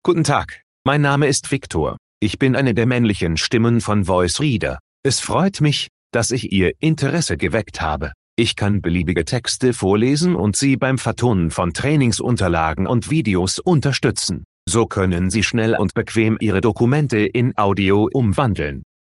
Voice Reader Home 22 - Männliche Stimme [Viktor] / German - Male voice [Viktor]
Voice Reader Home 22 ist die Sprachausgabe, mit verbesserten, verblüffend natürlich klingenden Stimmen für private Anwender.